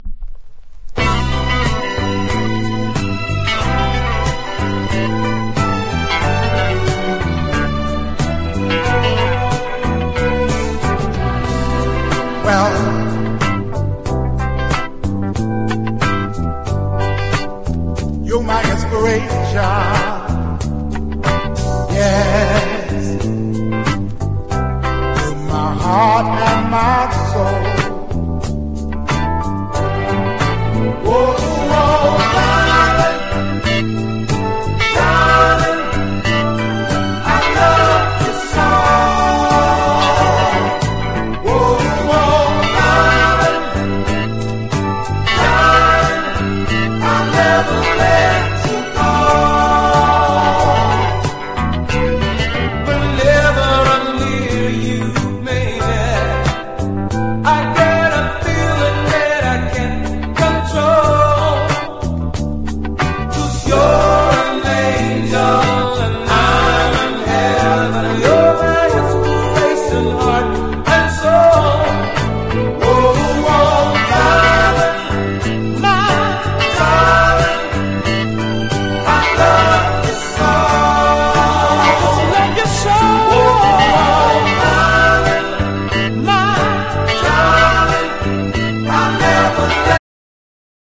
DELEGATIONの「OH HONEY」タイプのミディアムバラード。